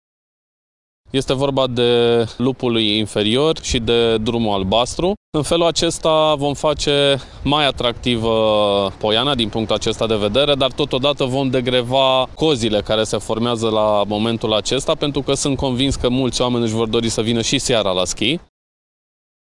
Viceprimarul municipiul Brașov, Sebastian Rusu.